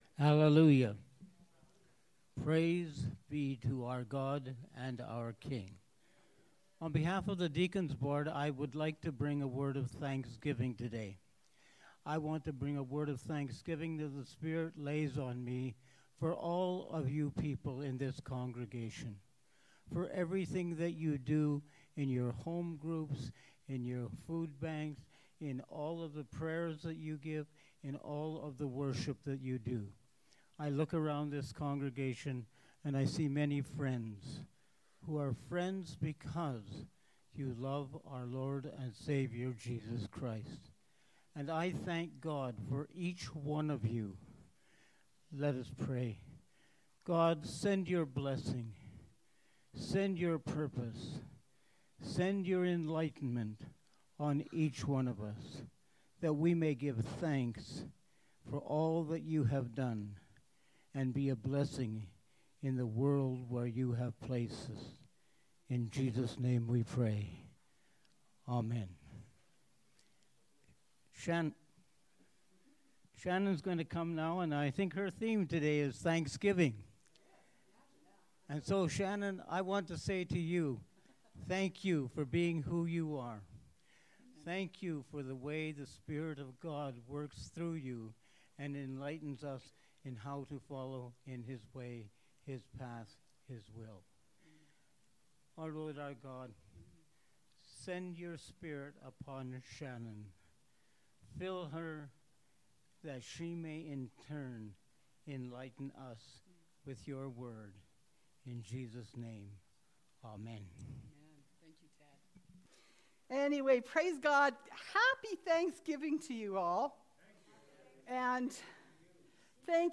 Pulpit Sermons